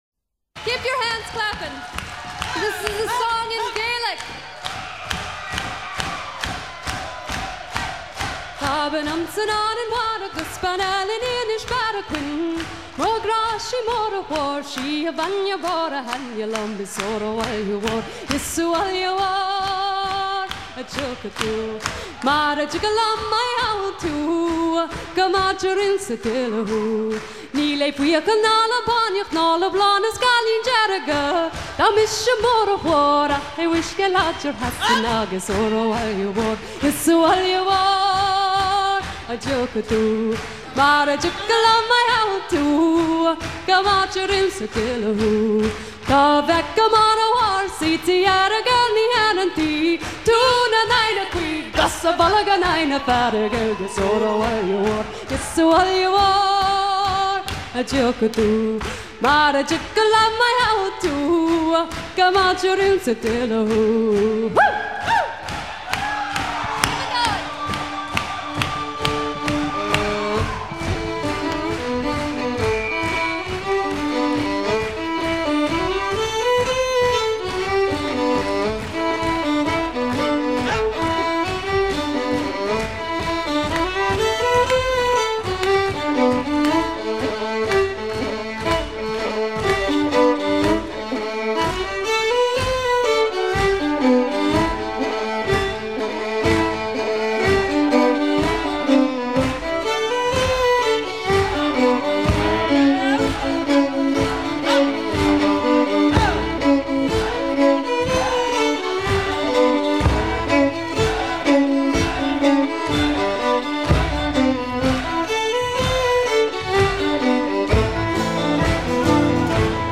chanson gaélique
Pièce musicale éditée